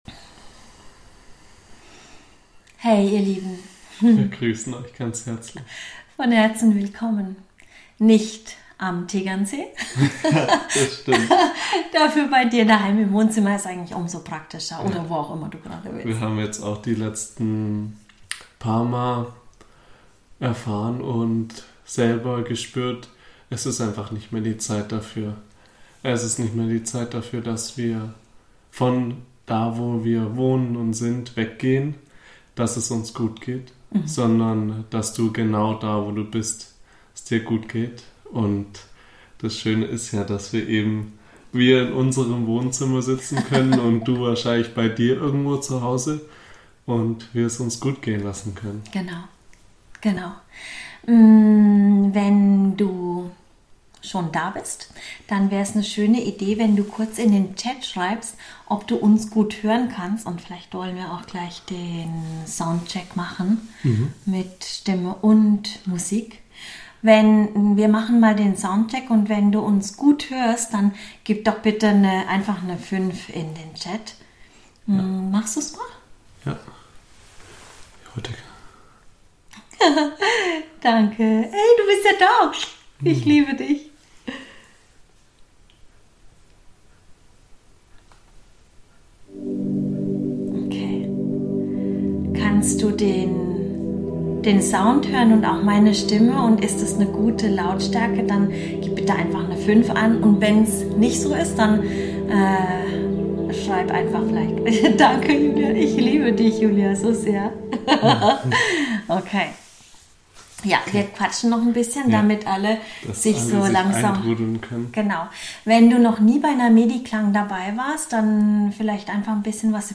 Lege dich in die Meditation, lass die Frequenzen aus Worten und Klängen in dich fließen, sodass Alles in dir in Harmonie schwingen kann!